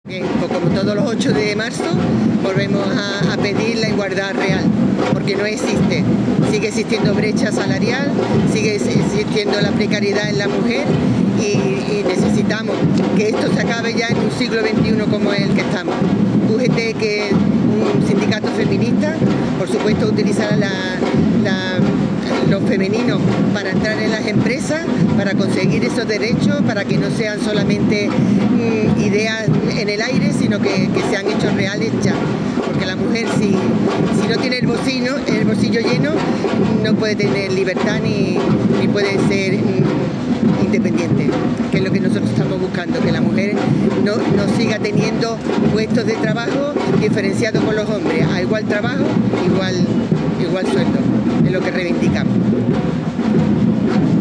El sindicato ha celebrado este viernes una concentración frente al Palacio de San Telmo en Sevilla con motivo del Día Internacional de las Mujeres, en la que ha vuelto a reivindicar la igualdad real entre mujeres y hombres, especialmente en el ámbito laboral, donde persisten importantes desigualdades.